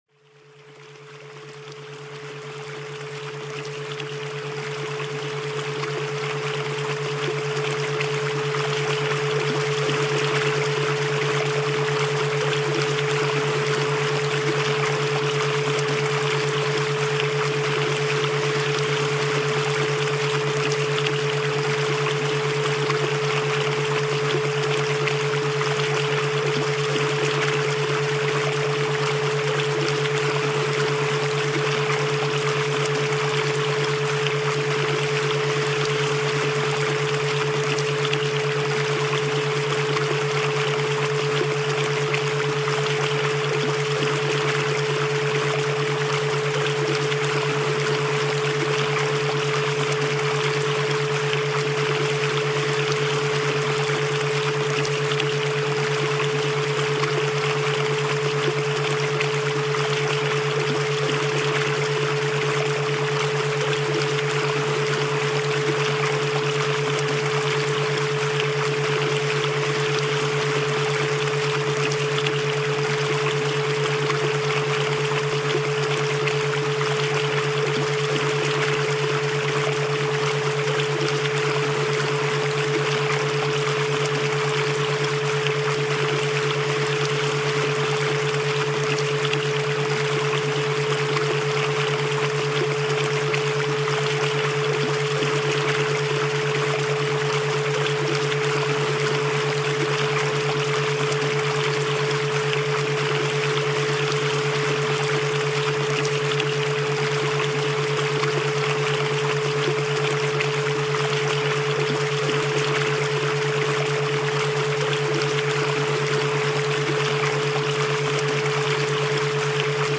432 Hz Cosmic Frequency Meditation – Raise Vibrations Naturally
Background Sounds, Programming Soundscapes